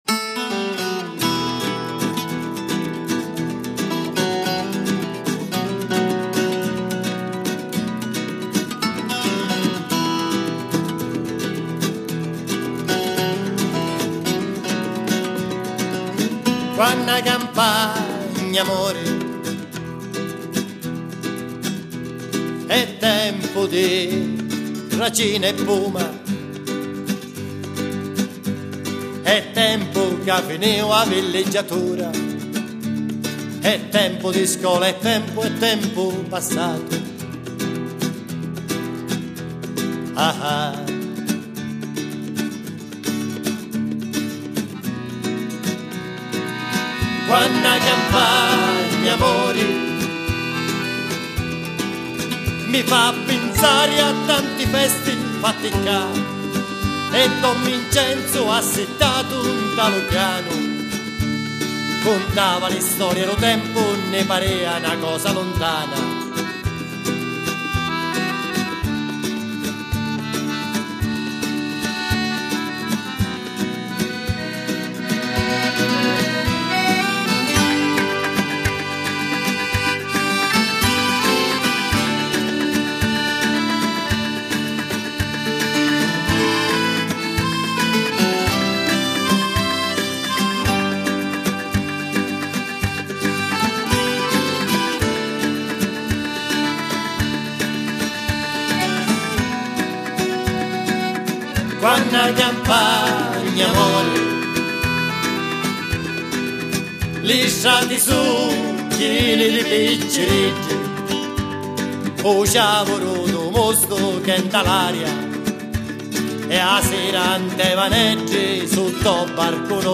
recuperare e riproporre la tradizione musicale siciliana